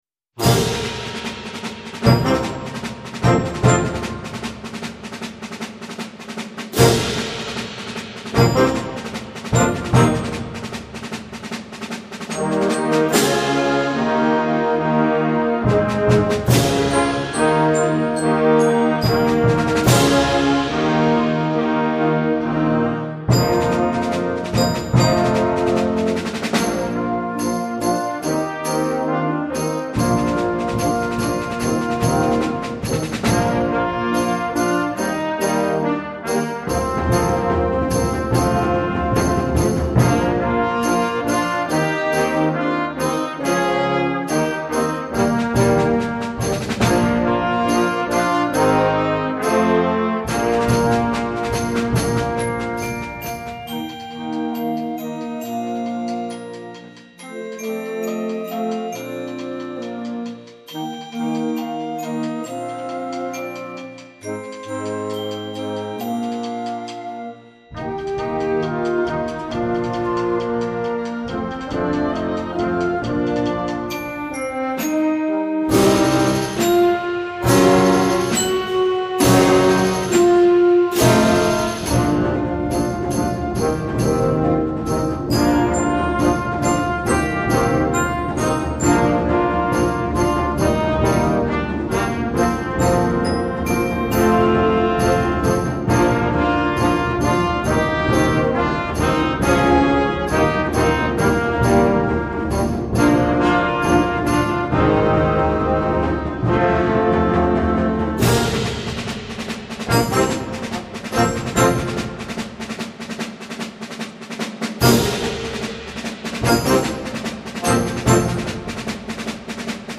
Concert Band
This energetic piece is a simulation of a horse race.
for very young band and optional choir